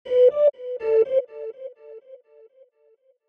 On The Move (Lead) 120 BPM.wav